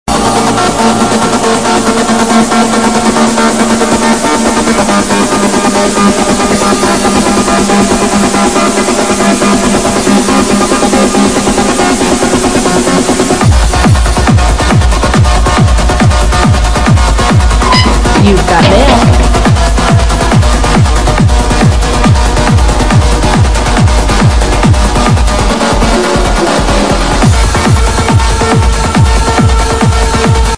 Question Tech-trancer